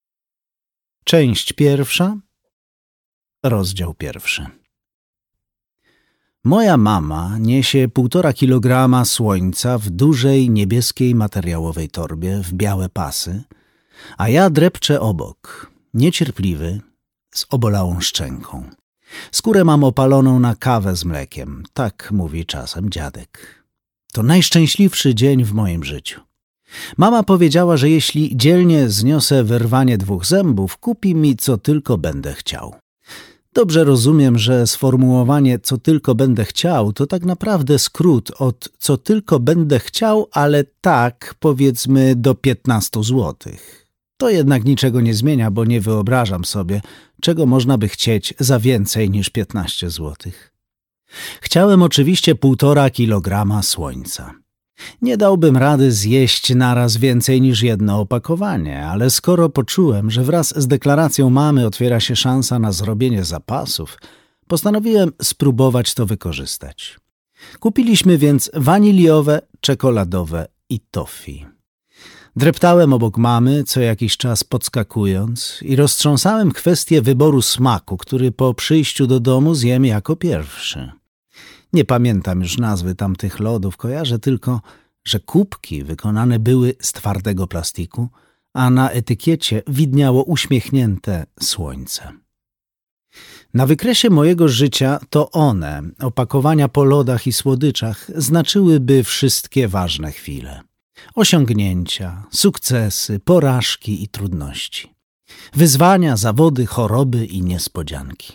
Przeczytaj i posłuchaj fragmentów Pobierz fragment zamknij × Darmowy fragment "(audiobook) Saturnin" Dostępność: Audiobooka odsłuchasz w aplikacji PulpUp.